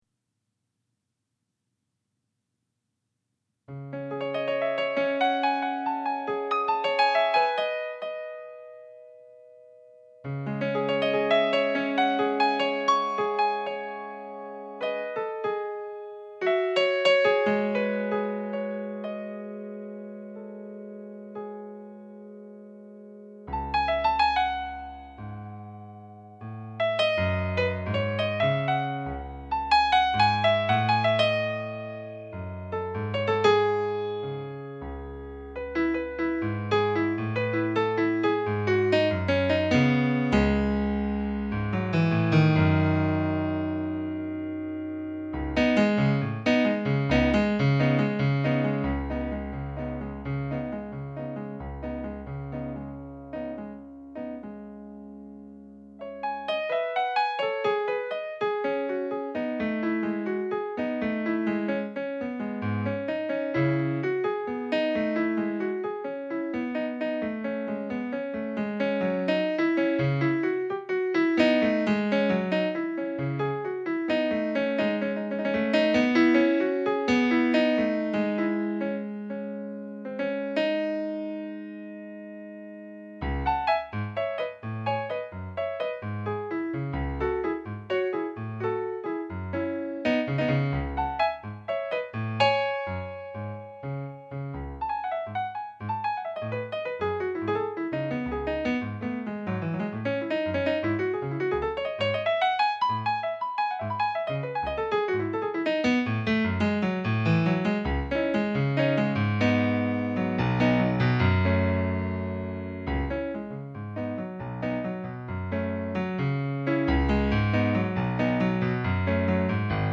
J’ai pris le temps hier, le 21 novembre,  de mettre sur ce blog une pièce pendant une session de pratiques.
Bien souvent se transformant en Do dièse